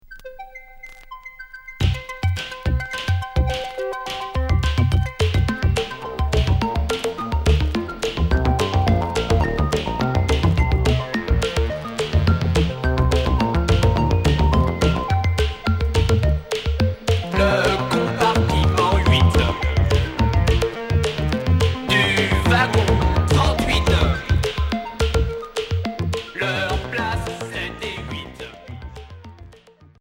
Minimal synth